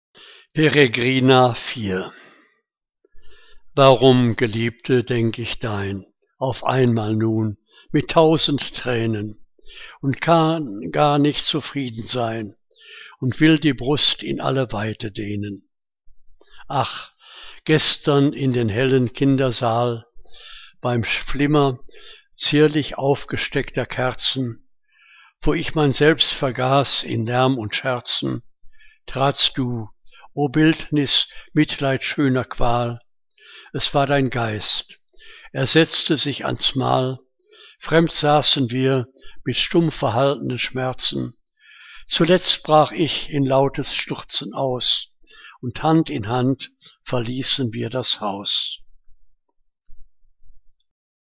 Liebeslyrik deutscher Dichter und Dichterinnen - gesprochen (Eduard Mörike)